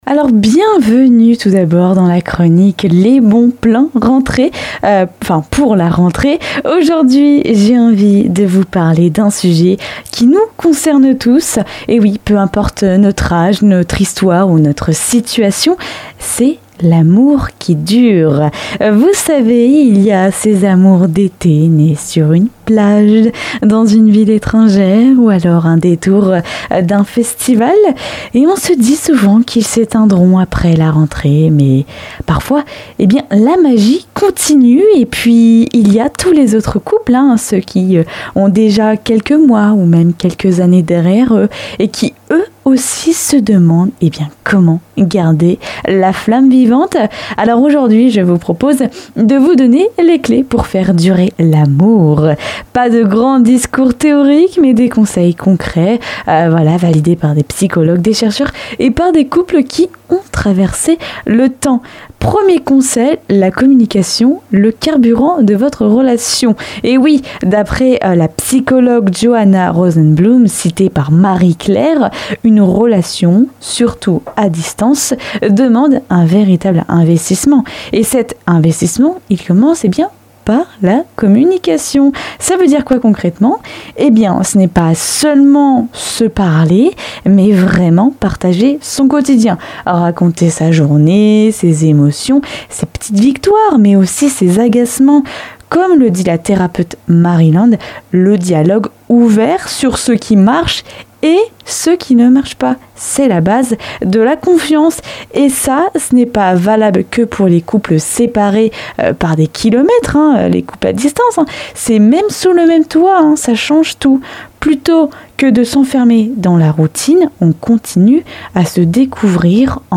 Bienvenue dans la chronique "Les Bons Plans Rentrée".